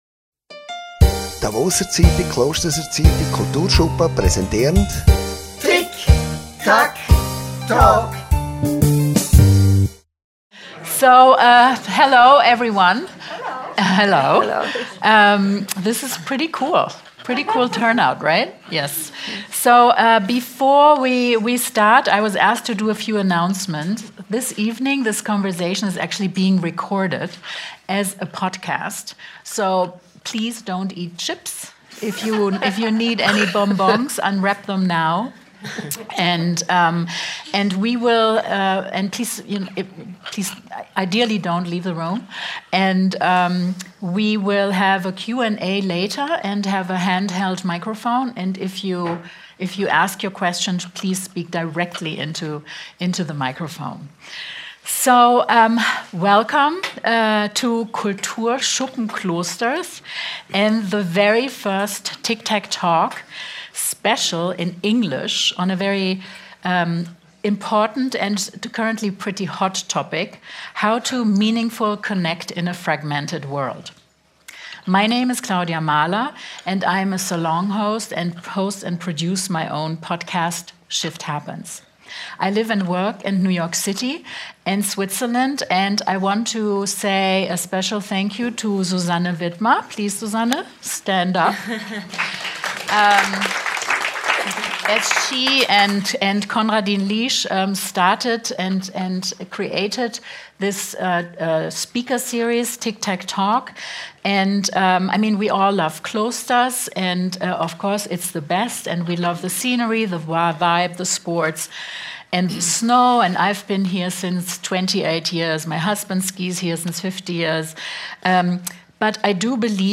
Talkshow und Podcast, präsentiert von der «Davoser Zeitung», «Klosterser Zeitung», Kulturschuppen Klosters.
Dabei werden heitere wie ernste Themen, Spannendes und Nebensächliches, auf lockere Art und Weise thematisiert. Und das Publikum kann Fragen stellen.